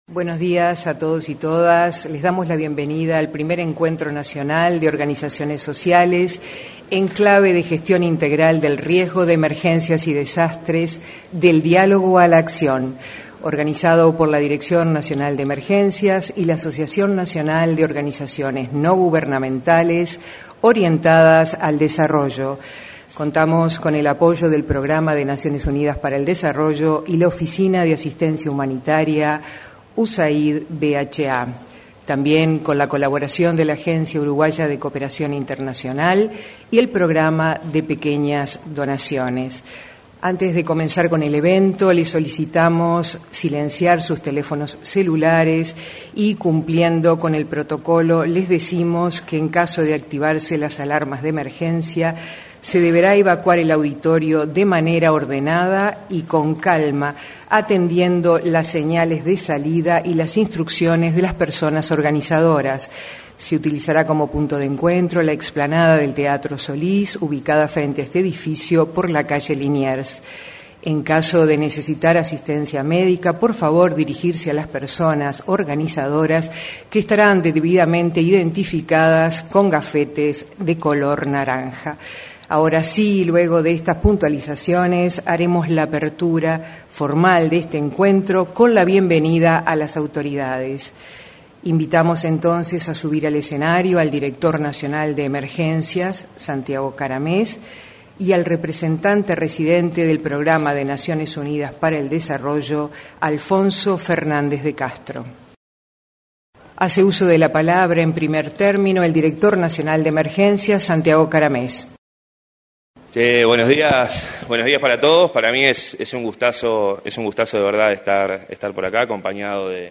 Este miércoles 16, se realizó, en el auditorio de la Torre Ejecutiva anexa, el primer encuentro nacional Del Diálogo a la Acción.
En la oportunidad, se expresaron el director nacional de Emergencias, Santiago Caramés, y el representante en Uruguay del Programa de las Naciones Unidas para el Desarrollo, Alfonso Fernández de Castro.